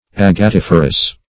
Search Result for " agatiferous" : The Collaborative International Dictionary of English v.0.48: Agatiferous \Ag`a*tif"er*ous\, a. [Agate + -ferous.] Containing or producing agates.